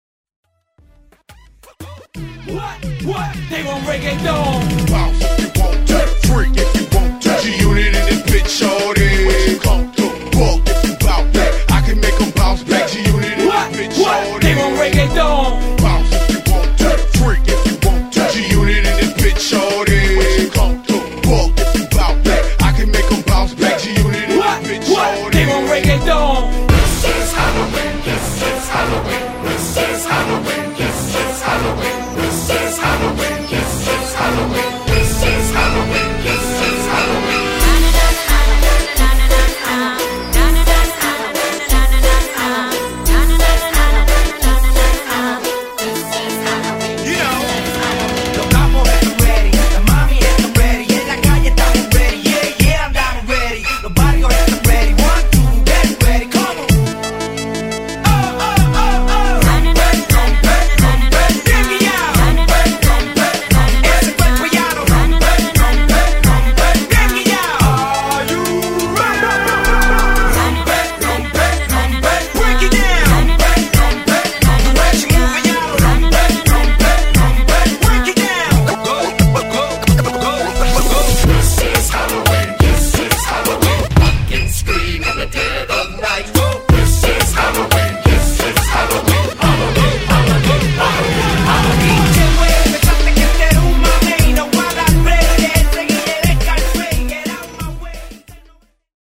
Genre: LATIN
Clean BPM: 100 Time